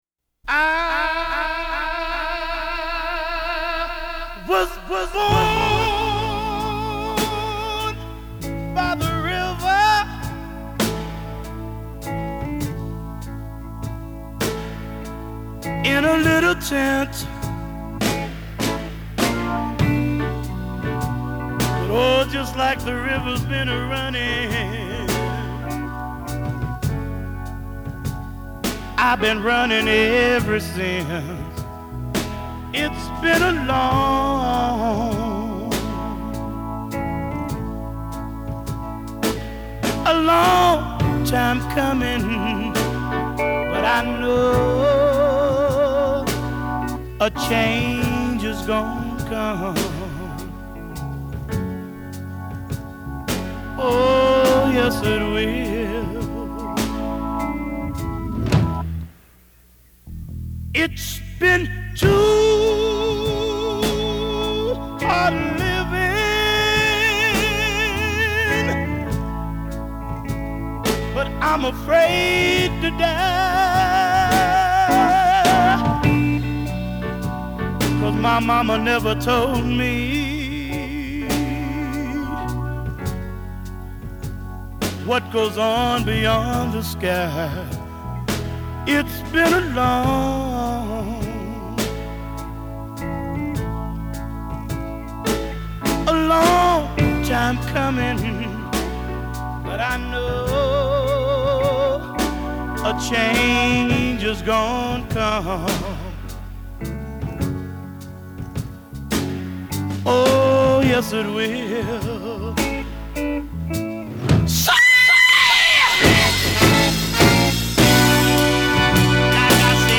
is downbeat, treacle-thick and just shy of 10 long minutes